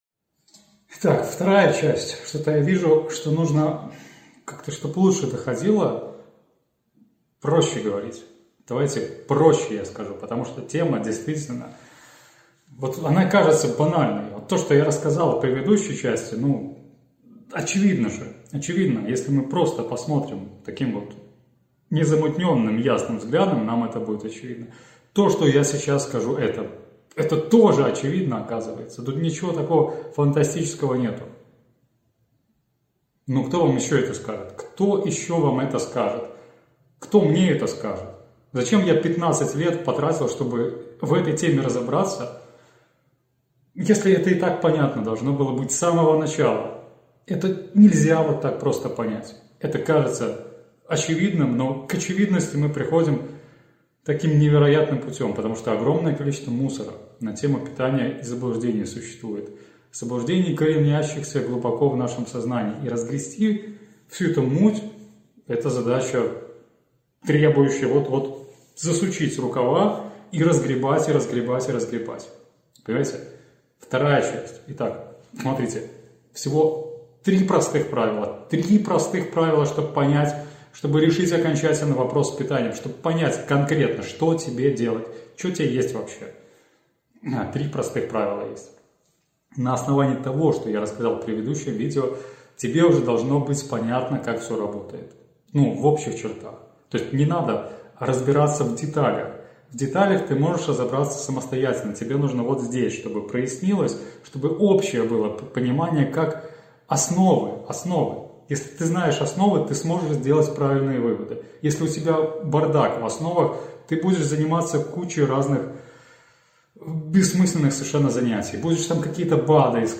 Голосовая заметка